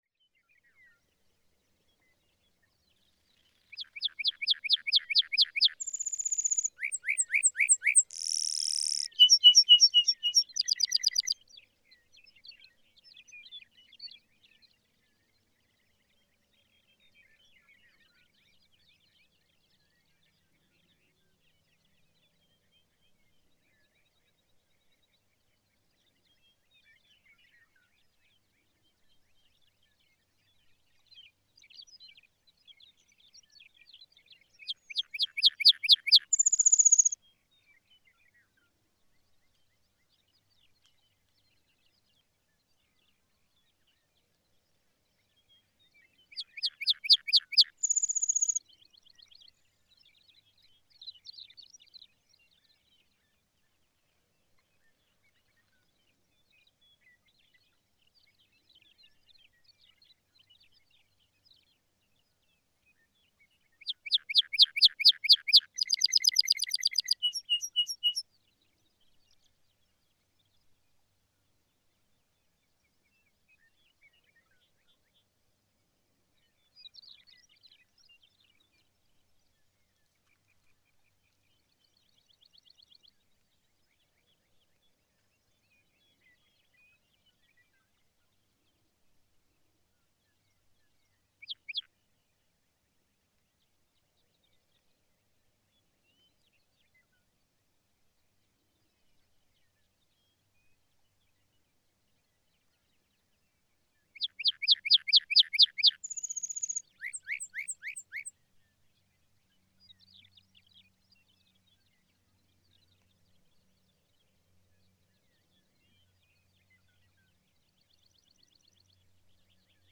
Lark bunting
Perched songs are often relatively brief, the flight songs much longer. His normal singing rate requires some human patience, as pauses between songs reach up to 40 seconds in this recording. I could have edited out the silent intervals, of course, but I chose to let this lark bunting have his say at his own pace.
Murphy's Pasture, Pawnee National Grassland, Colorado.
549_Lark_Bunting.mp3